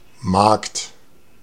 Ääntäminen
US : IPA : [ˈmeɪ.dᵊn]